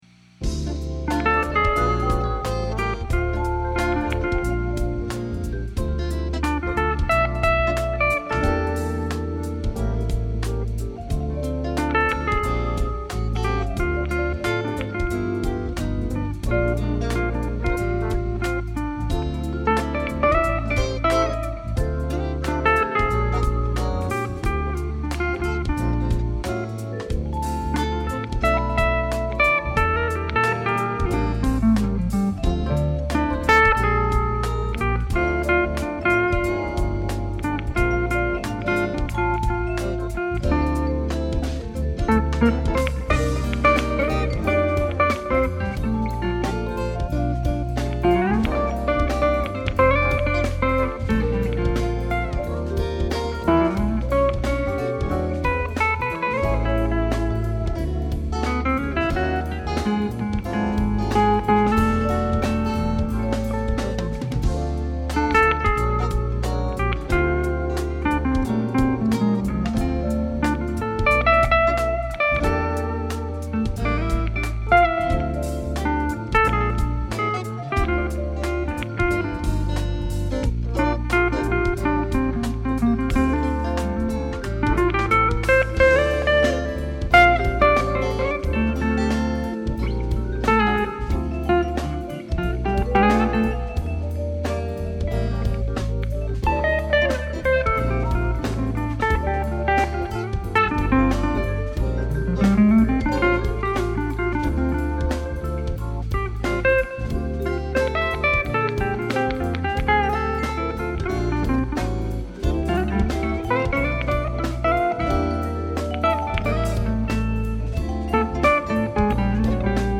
Guitar Works
Smooth Jazz